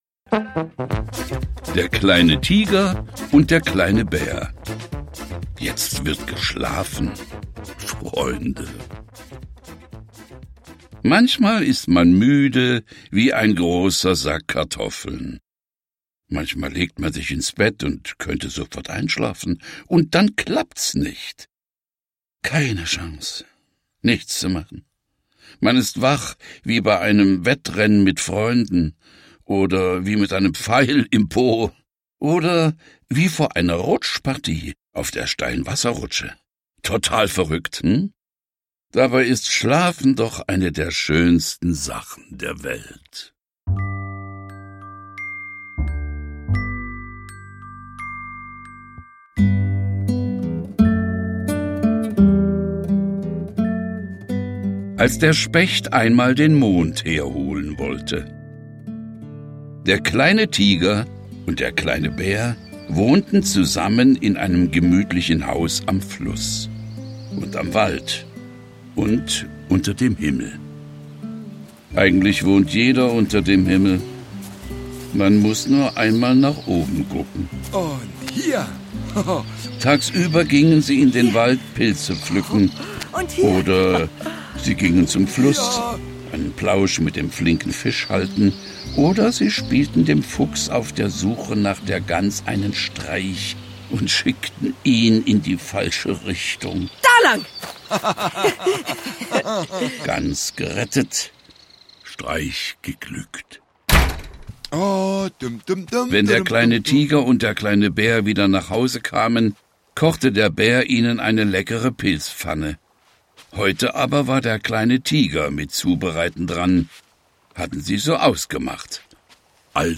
Jetzt wird geschlafen, Freunde! Teil 2 Gutenachtgeschichten mit Tiger und Bär